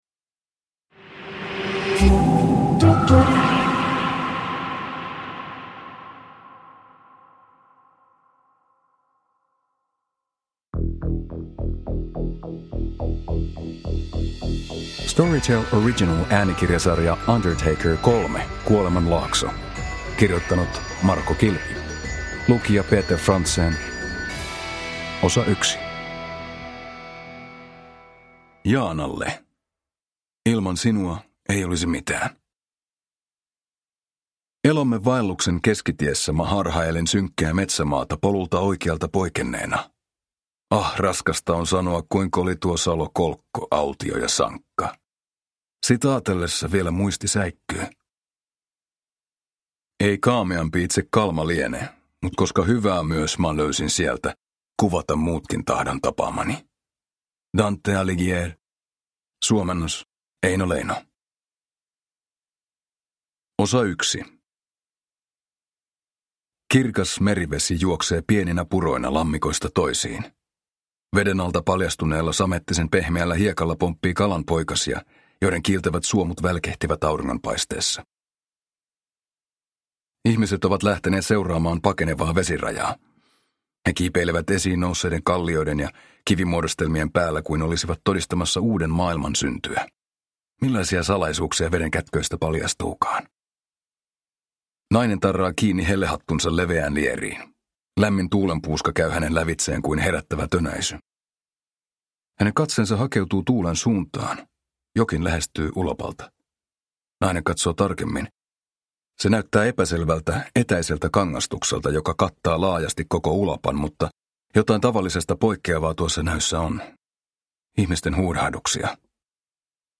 Uppläsare: Peter Franzén